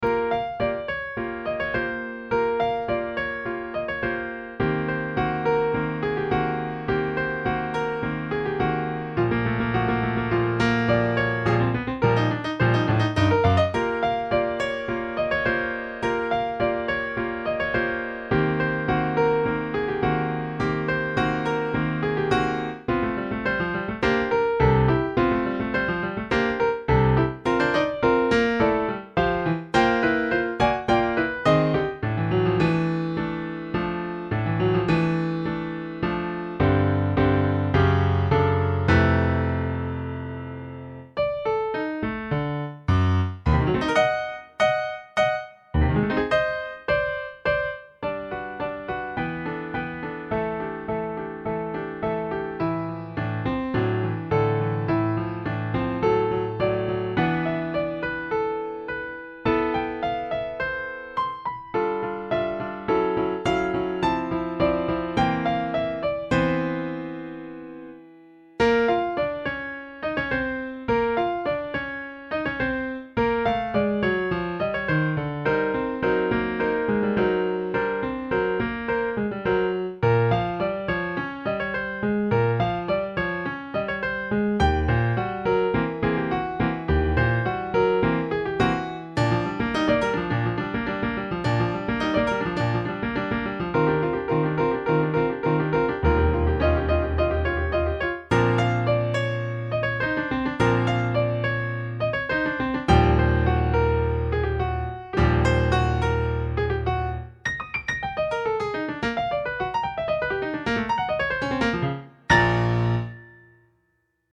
ф-но) исп.автор